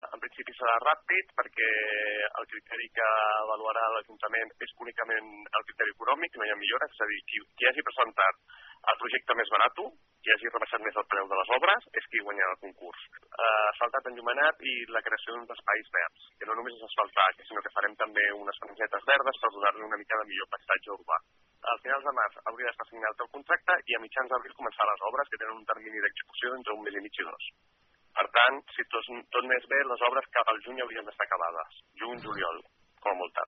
Són declaracions del fins ara regidor d’obres, Josep Rueda.